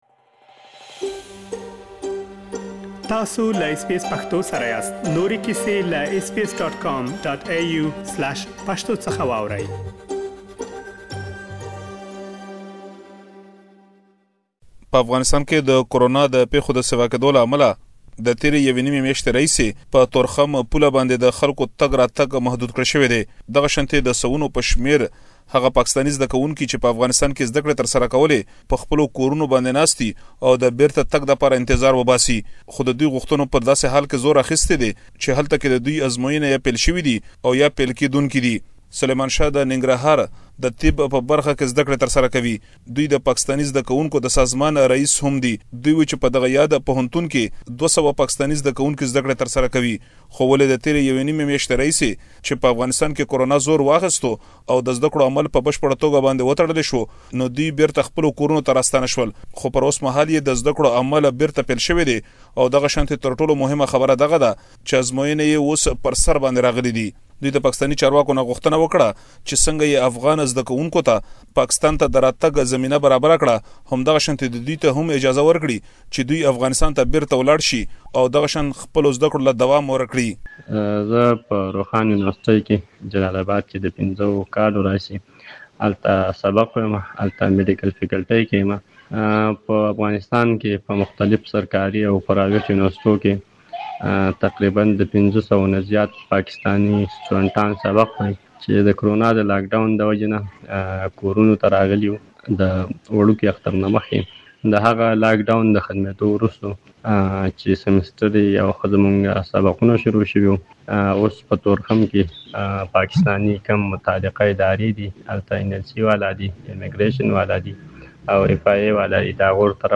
تاسو ته مو د ګڼو پاکستاني زده کوونکو غږونه راخيستي چې دلته يې اوريدلی شئ.